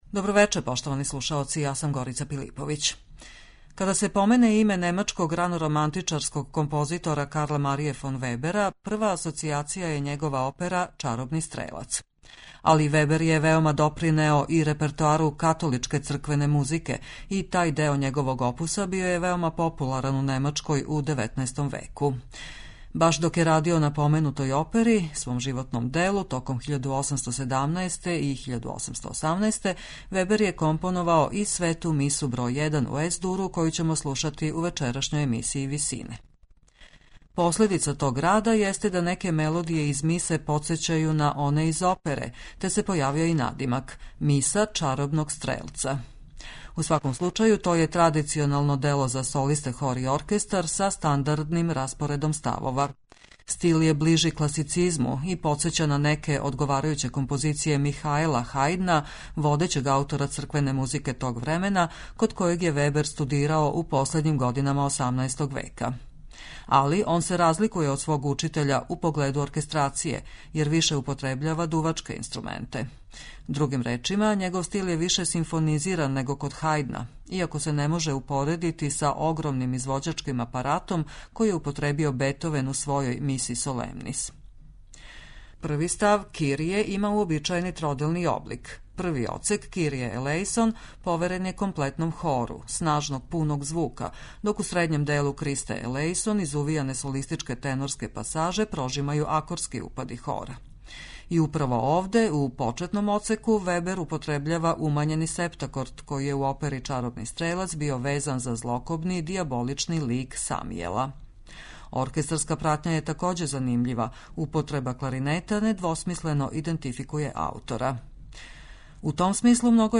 Карл Марија фон Вебер: Света миса бр. 1 у Ес-дуру
На крају програма, у ВИСИНАМА представљамо медитативне и духовне композиције аутора свих конфесија и епоха.